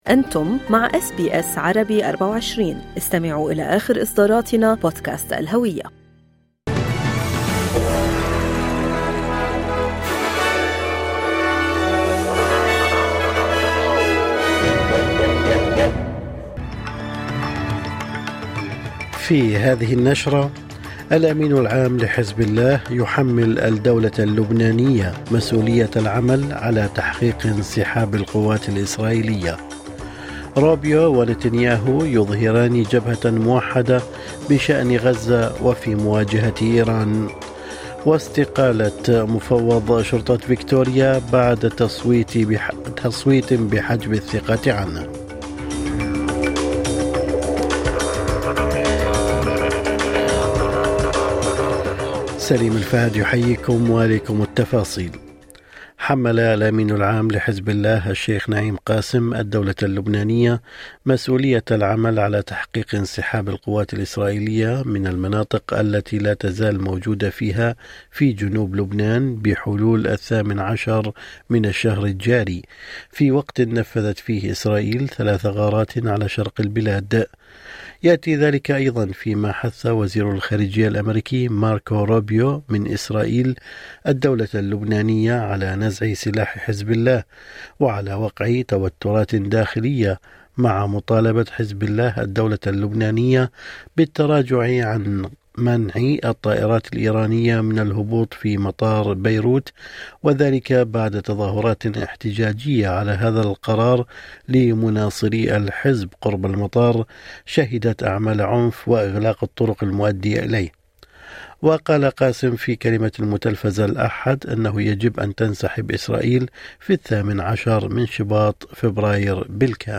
نشرة أخبار الصباح 17/2/2025